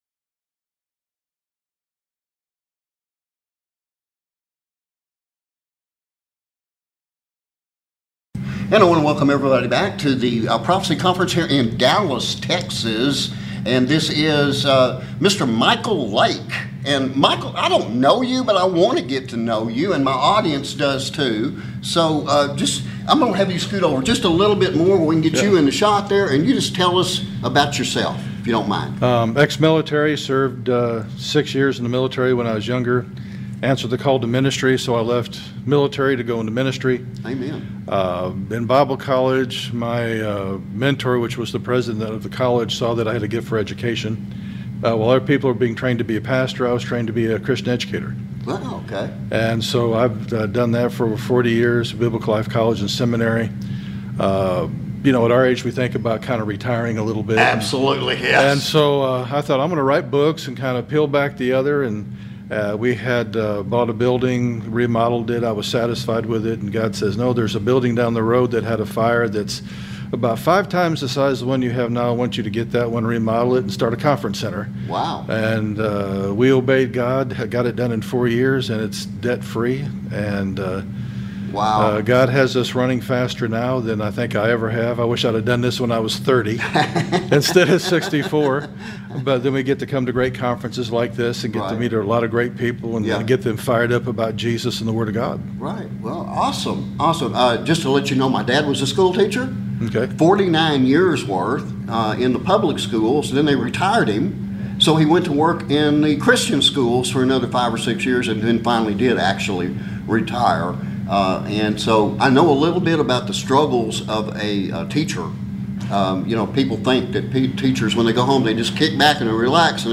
We do apologize for the Sound issues beyond our control caused by fans operating inside our Interview Room.